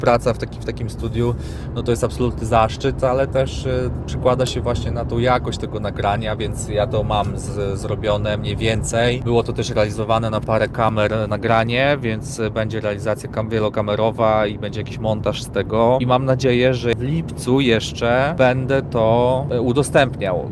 utwór symfoniczny